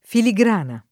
filigrana [ fili g r # na ]